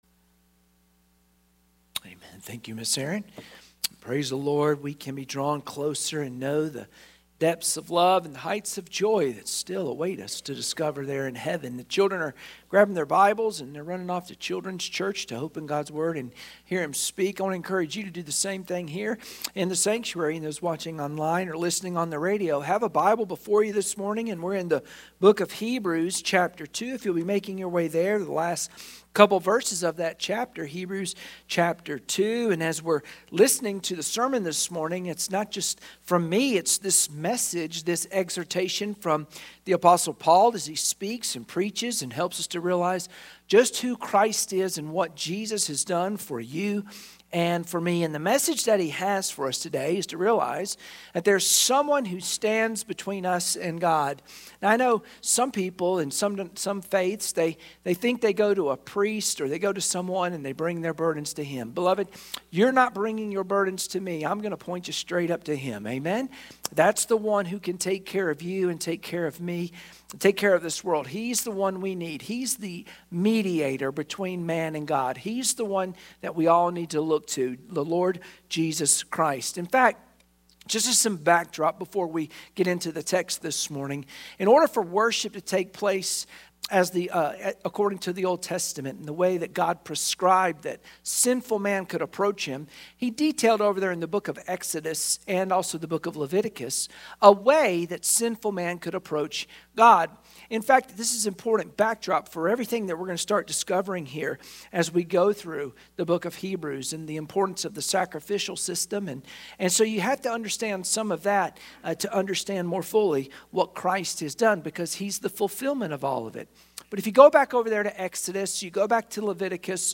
Sunday Morning Worship Passage: Hebrews 2:17 Service Type: Sunday Morning Worship Share this